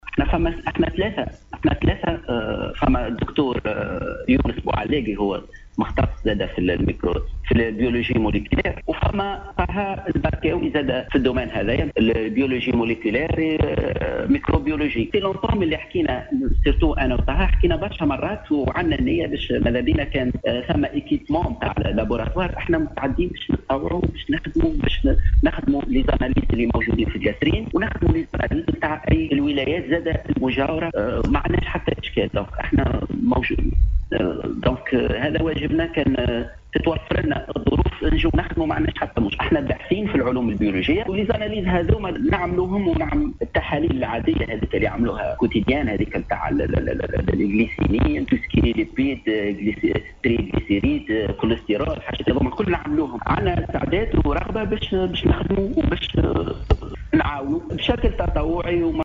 الباحث في العلوم البيولوجية
يتحدث لاذاعة السيليوم اف ام